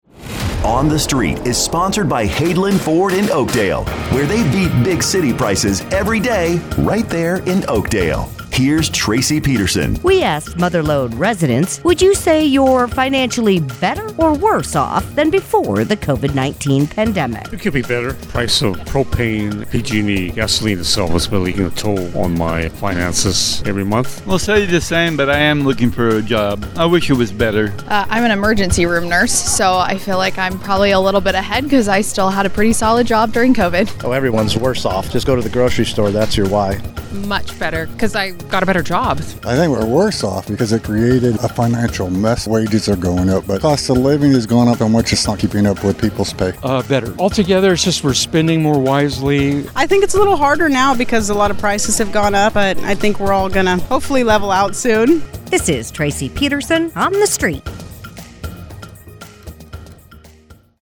asks Mother Lode residents, “Would you say you’re financially better or worse off than before the COVID-19 pandemic?”